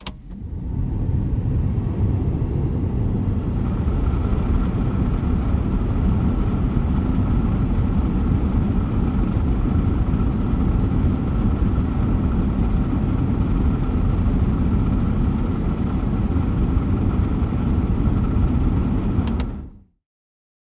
GMC Jimmy interior point of view as A/C runs at low and high speeds. Air Conditioner